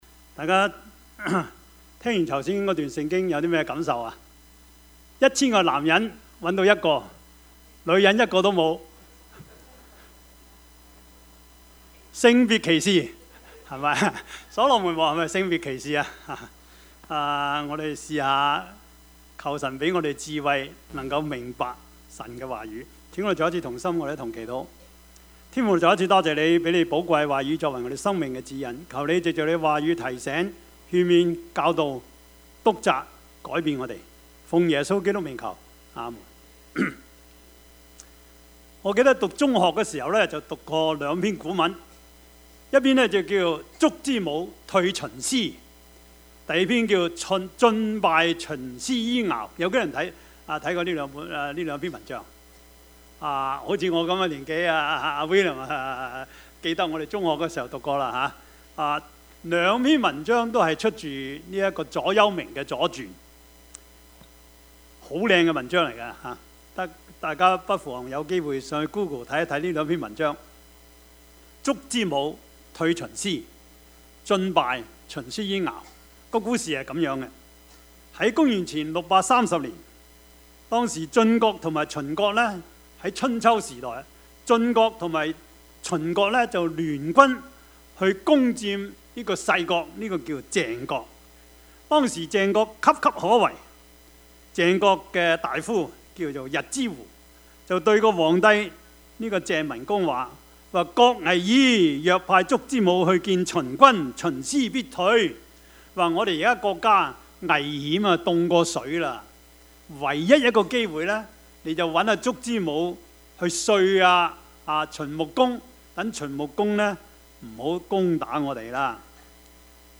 Passage: 傳道書 7:15-29 Service Type: 主日崇拜
Topics: 主日證道 « 跟隨基督的意義 可以不可以?